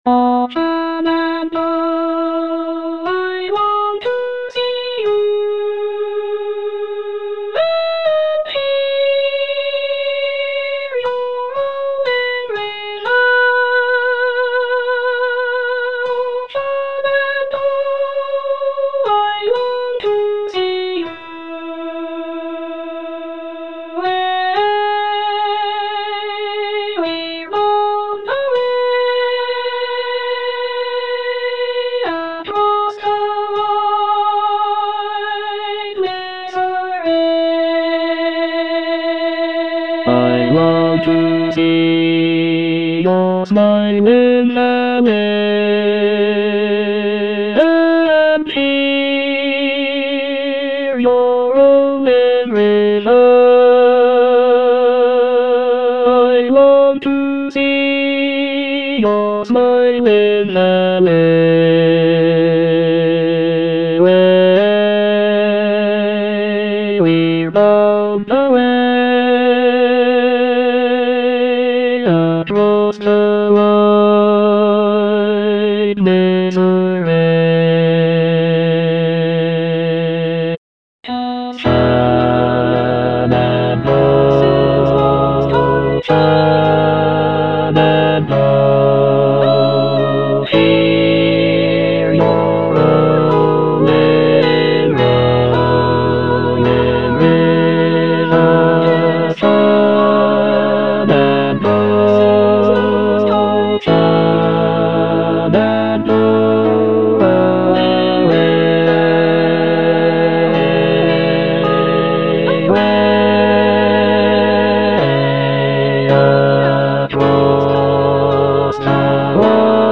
ANONYMOUS (ARR. J. ERB) - SHENANDOAH Bass I (Emphasised voice and other voices) Ads stop: auto-stop